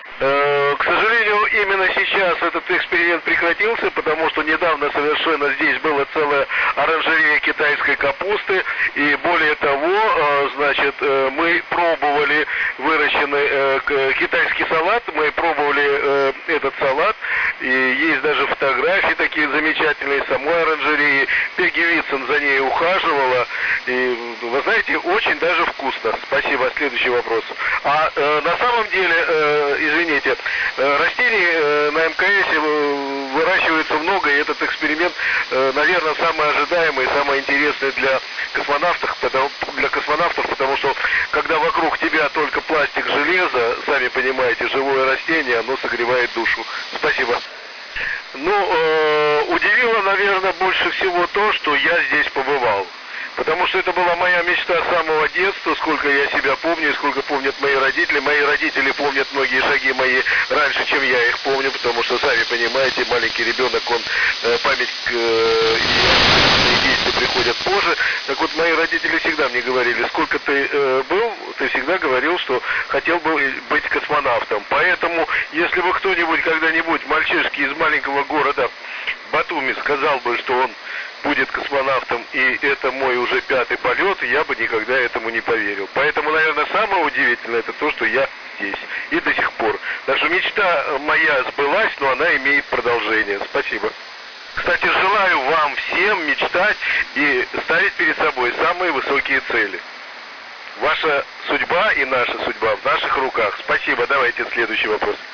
Сеанс связи Ф.Юрчихина с Уфой (продолжение)